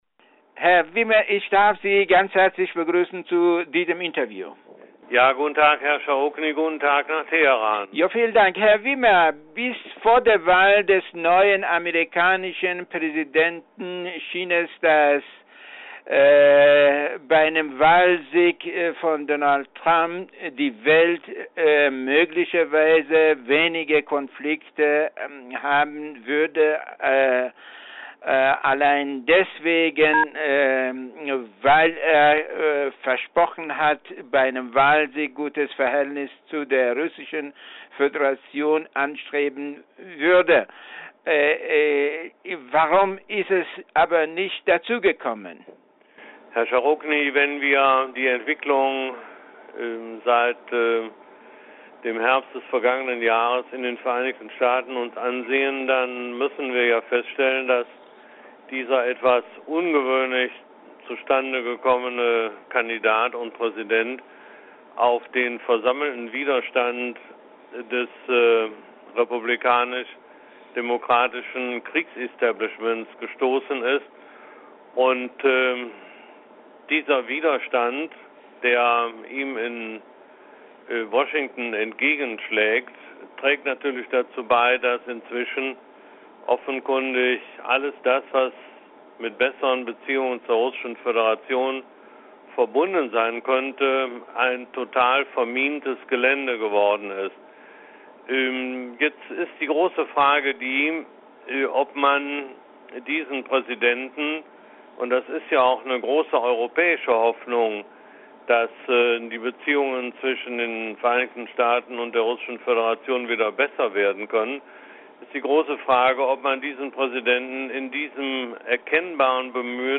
Interview mit Willy Wimmer